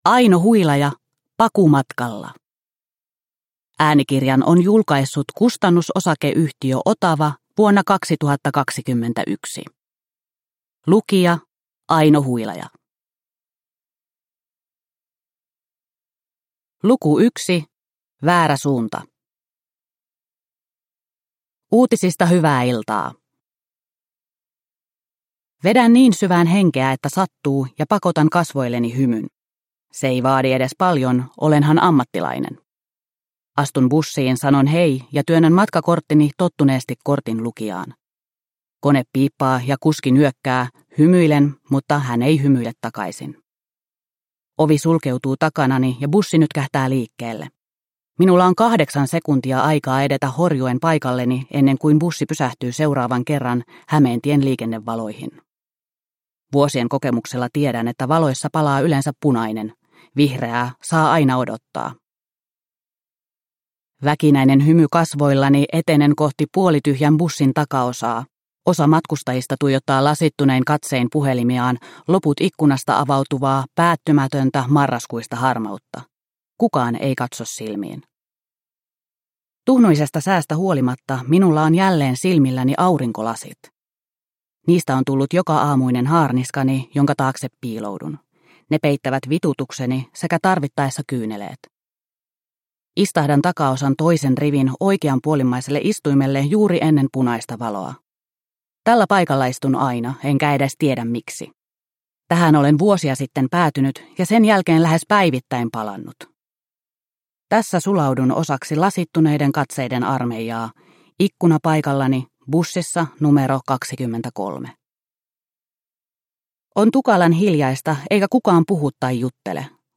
Pakumatkalla – Ljudbok – Laddas ner